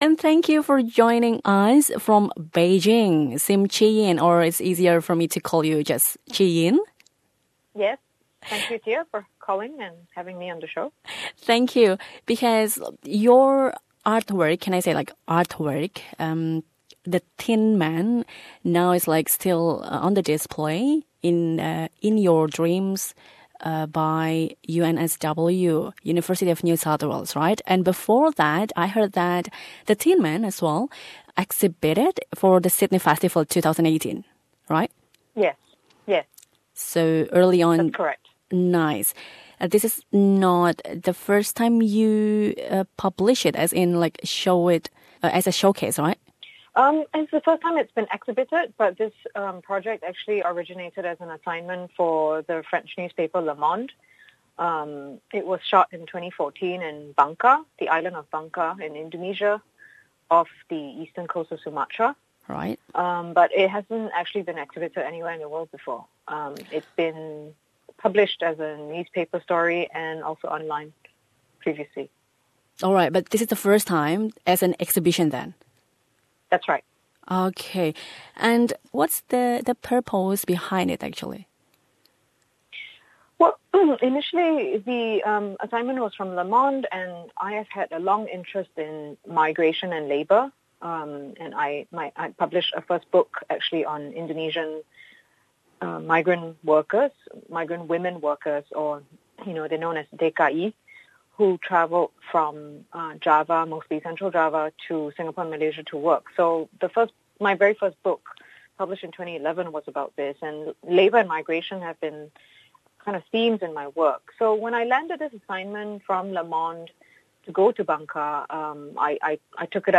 Wawancara ini dalam bahasa Inggris.